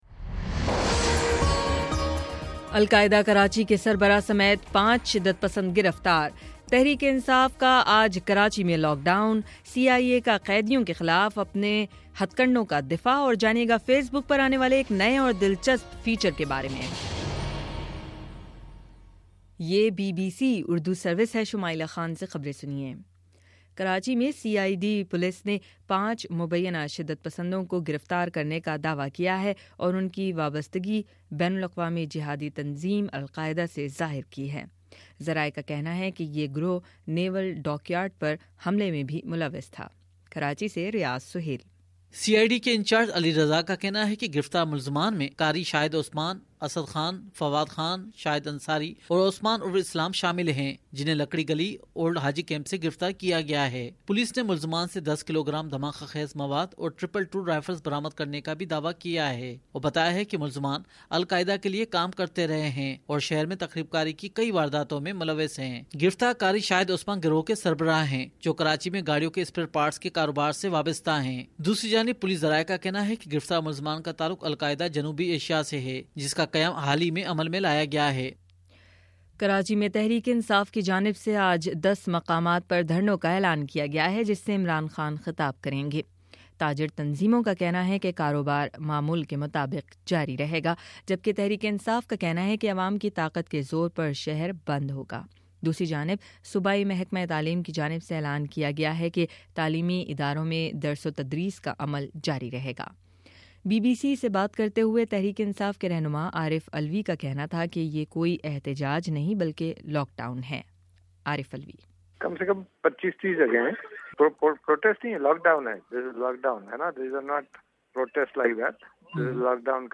دسمبر12: صبح نو بجے کا نیوز بُلیٹن